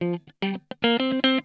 PICKIN 2.wav